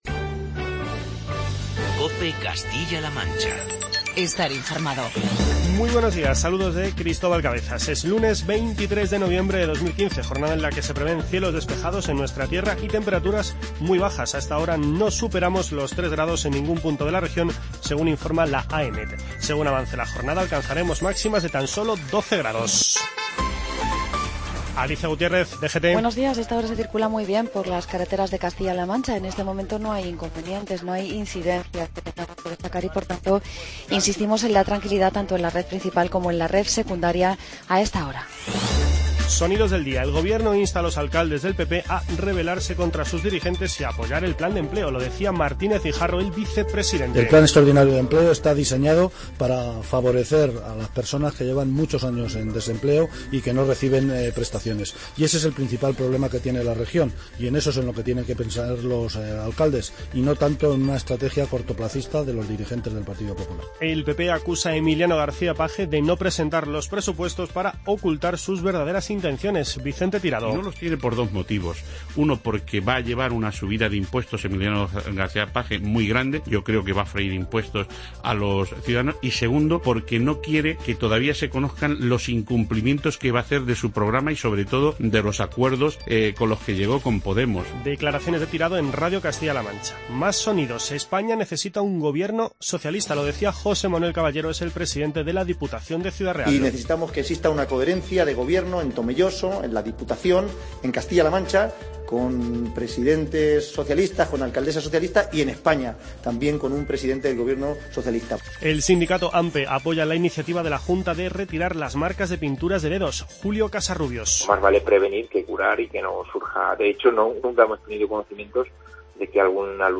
Informativo regional y provincial
Hoy destacamos los testimonios de José Luis Martínez Guijarro, Vicente Tirado y José Manuel Caballero